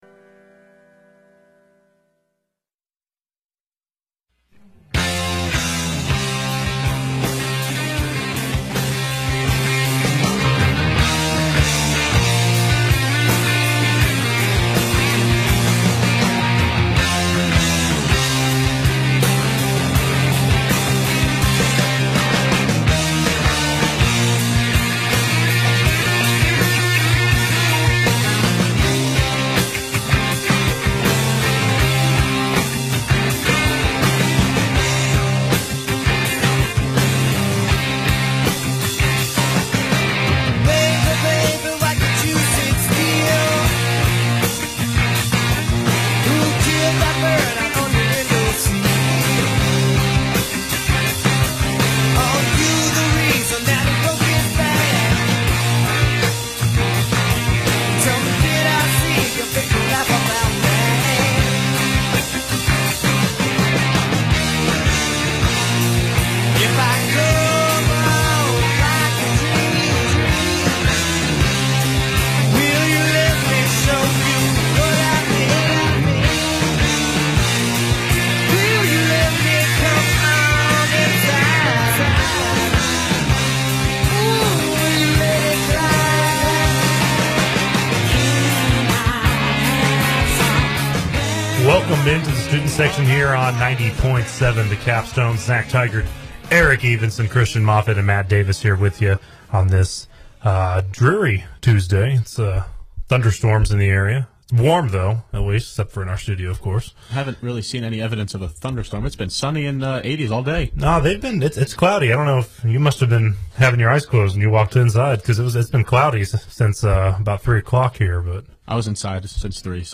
WVUA-FM's flagship sports talk show: The Student Section